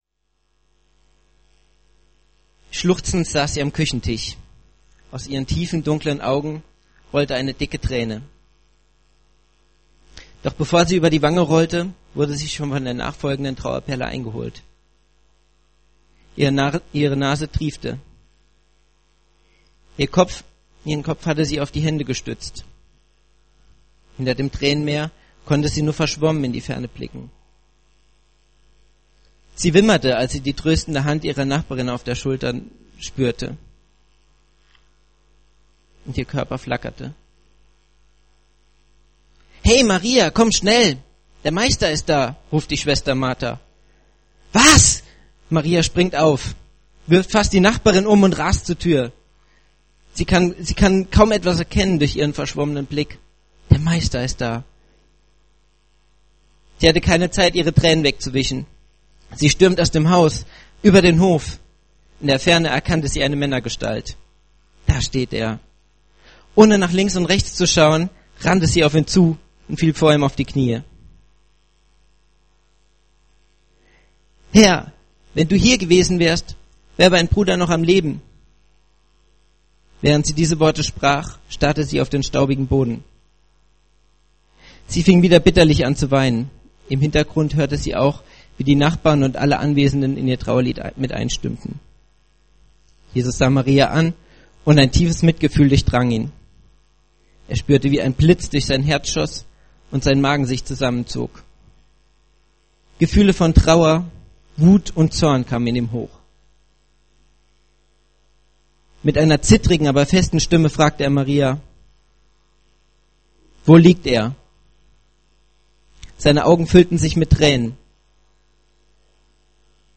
in seiner Predigt vom 1. Advent